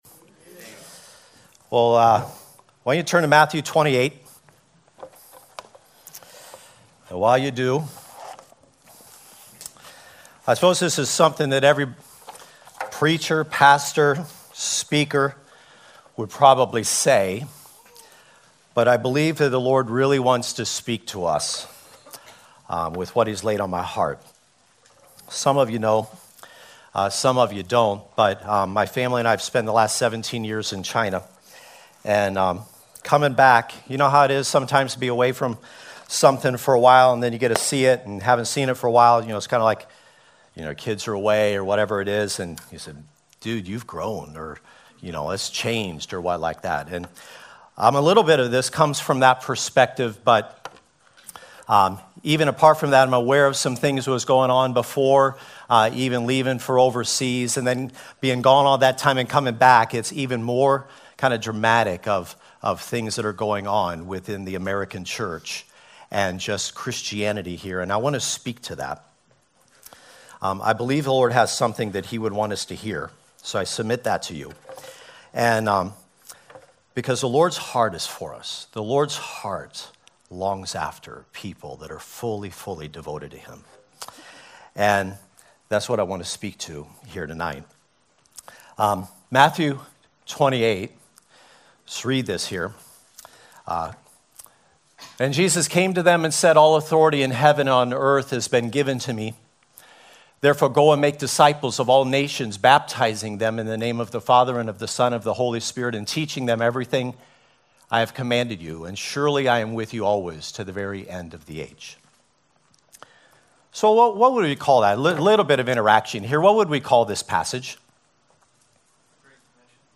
We feature verse by verse teachings through the Bible, topical messages, and updates from the staff and lead team.